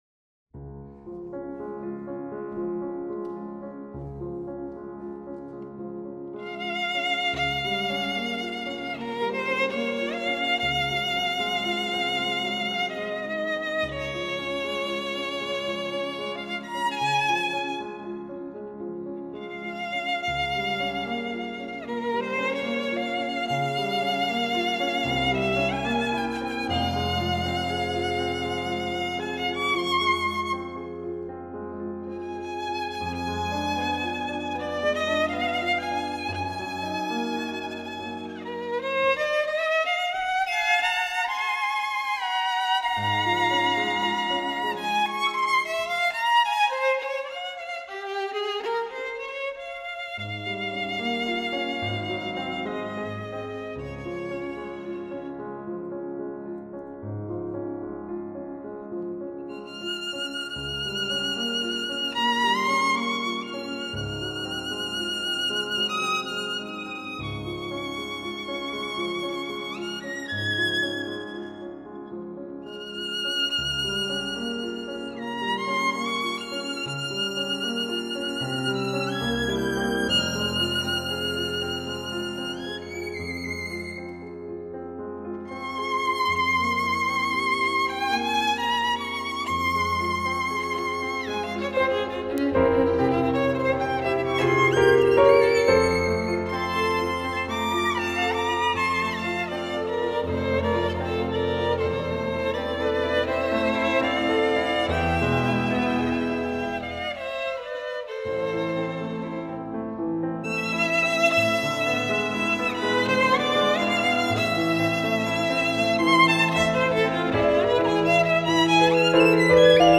无论从演技到音质都无可挑剔，曲调优美，演奏细腻，丝丝入扣，是发烧友不可多得的一张试音天碟。